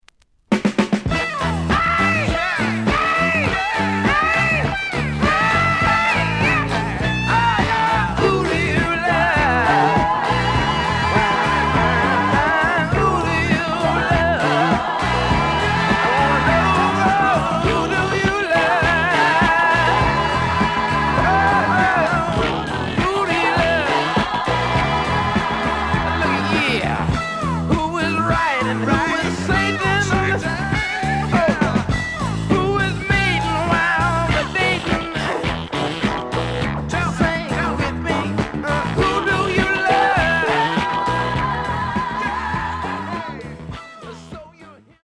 The listen sample is recorded from the actual item.
●Genre: Funk, 70's Funk
B side plays good.)